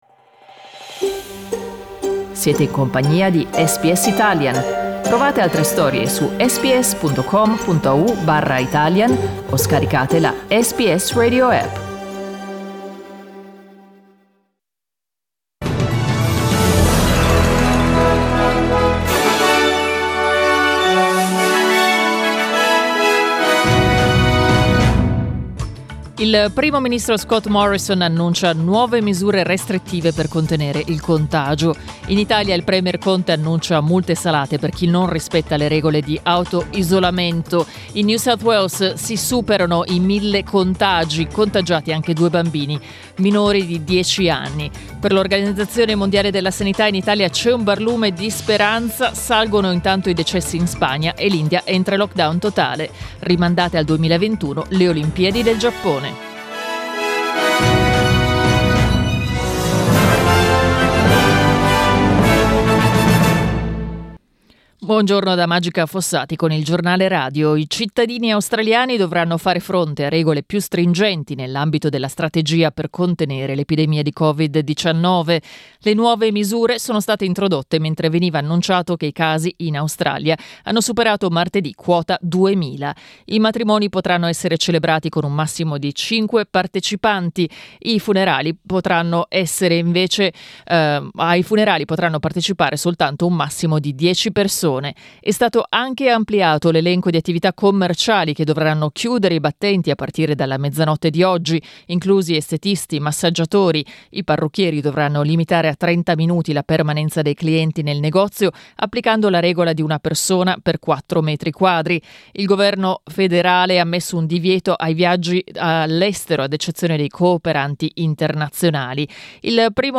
Our news bulletin (in Italian).